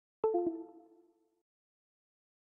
Disconnect Sound.mp3